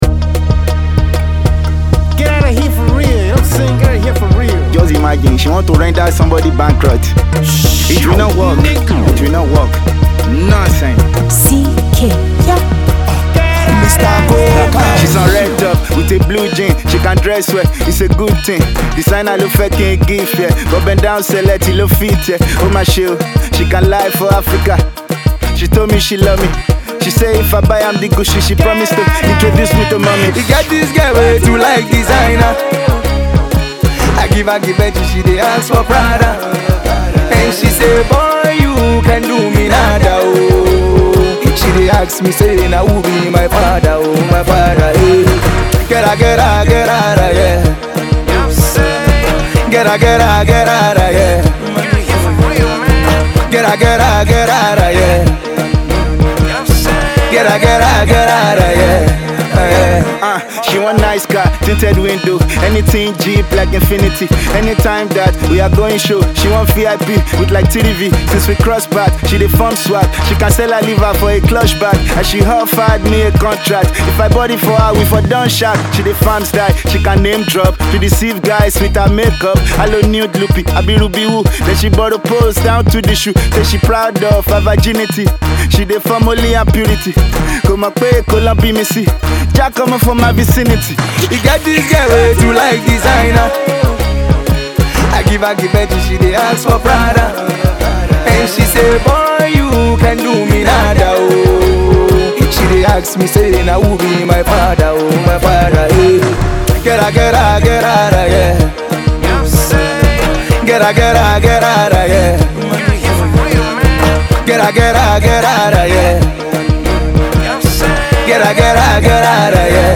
in a very catchy fashion